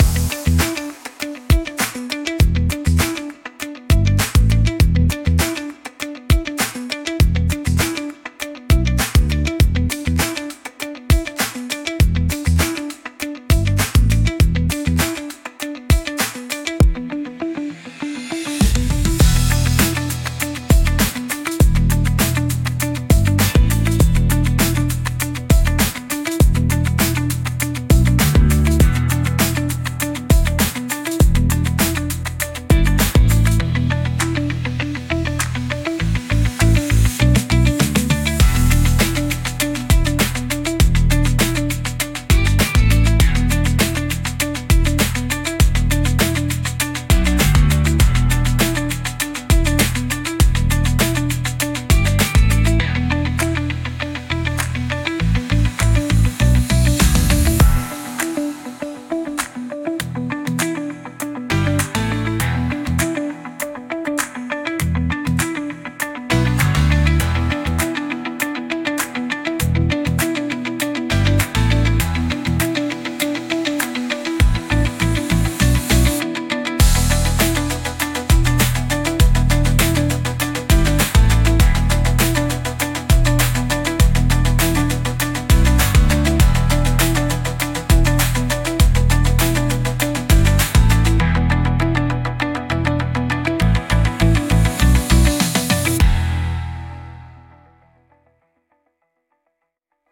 Home Download DANCE/ELECTRO/HOUSE